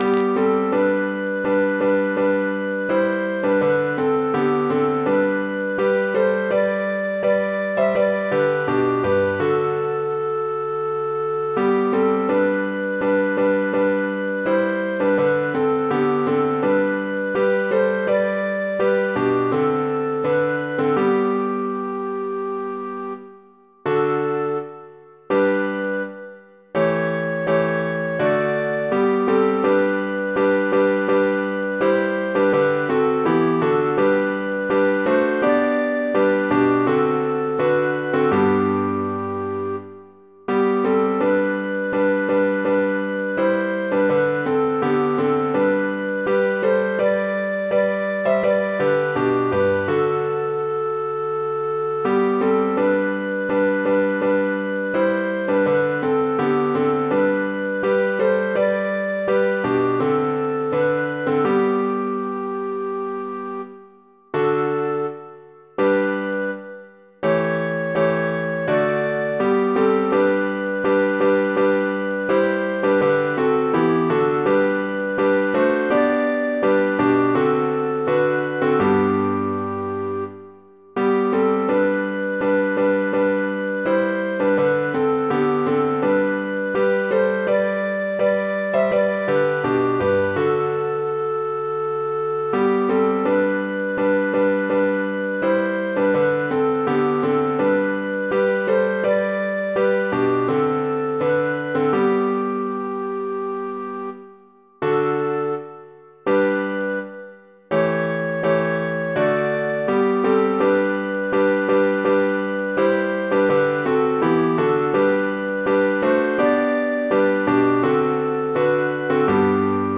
piano, keyboard, keys
Мелодия за разучаване: